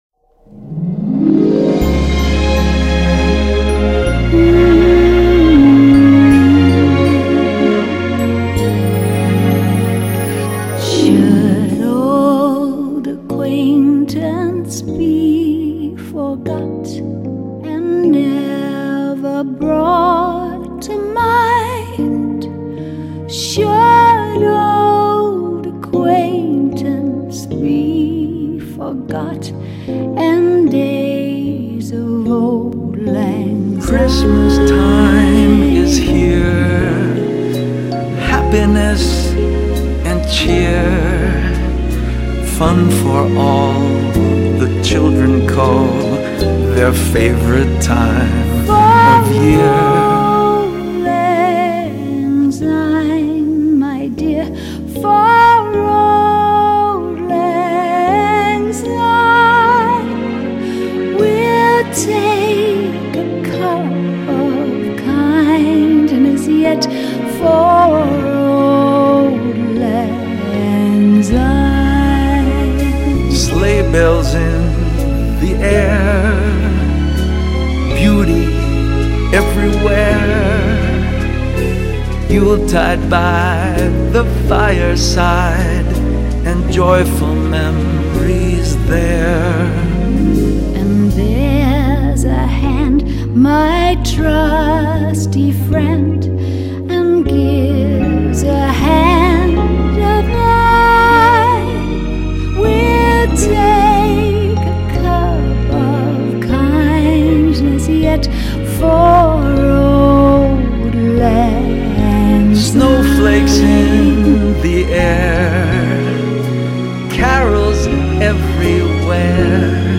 音乐类型： Pop, X'mas　　　　　　　　　　　　　.